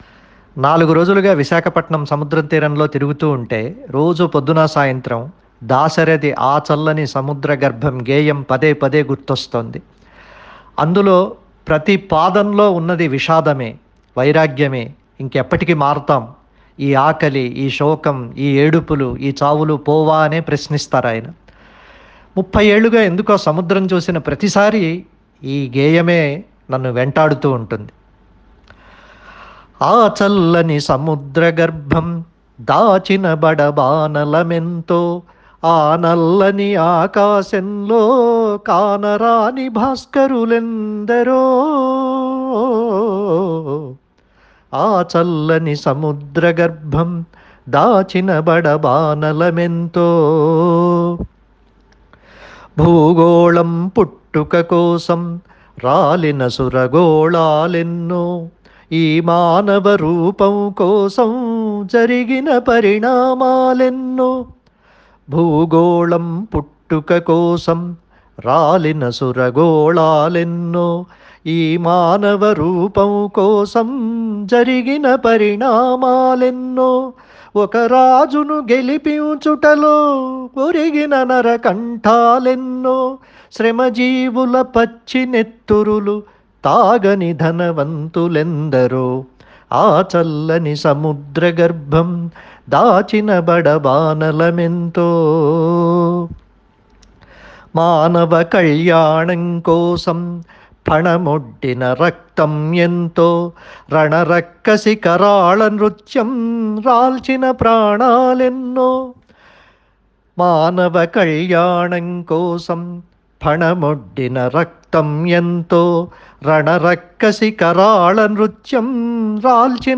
Vizag Waves…:
Vizag-Waves.ogg